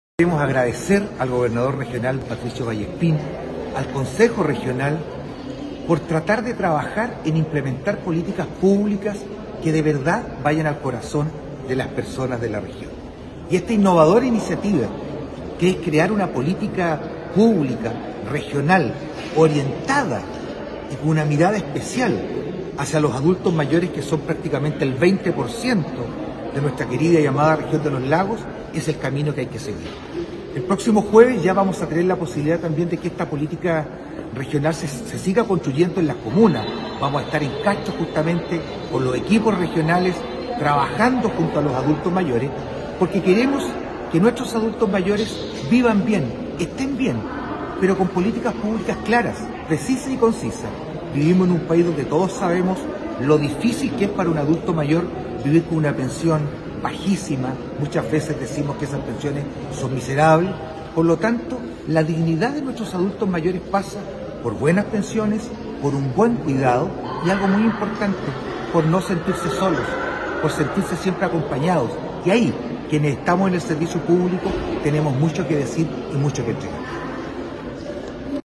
Así se refirió el alcalde Juan Eduardo Vera al participar junto al gobernador regional Patricio Vallespín del lanzamiento de una política regional que está dirigida y orientada a ese segmento de la población y que se construirá a partir de las realidades de cada una de las comunas pertenecientes a la región de Los Lagos.